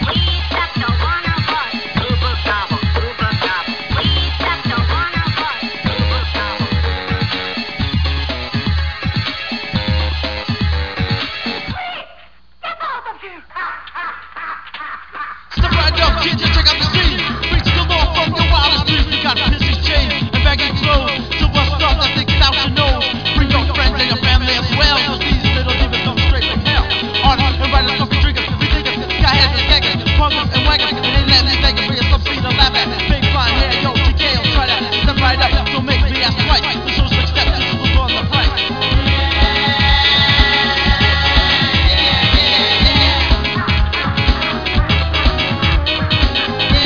electronic music
keyboards, samplers